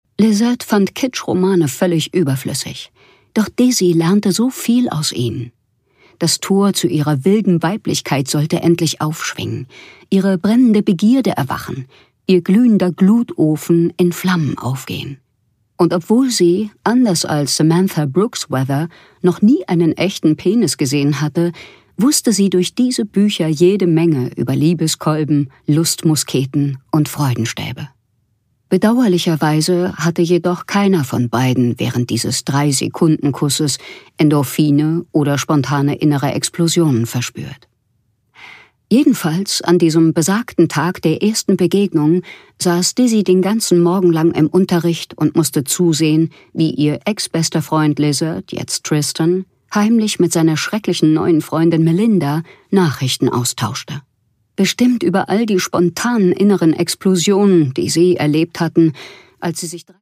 Jandy Nelson: Wenn unsere Welt kippt (Ungekürzte Lesung)
Produkttyp: Hörbuch-Download